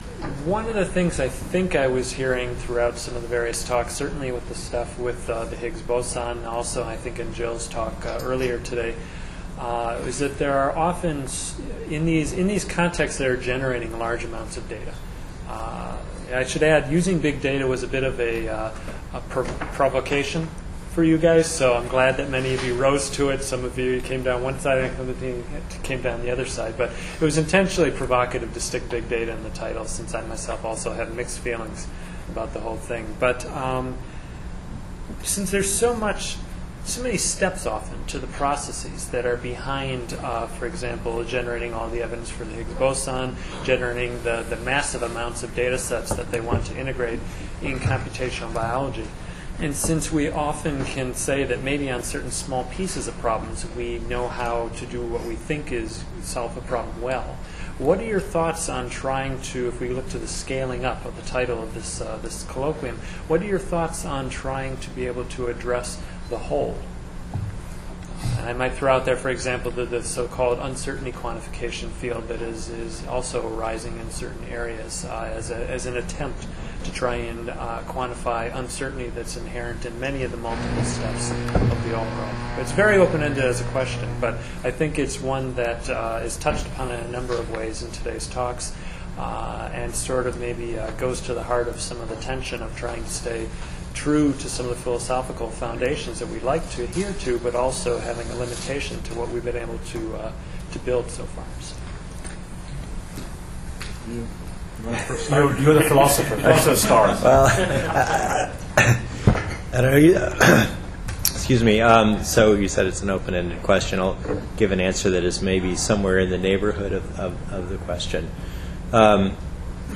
5.-Panel-Discussion.mp3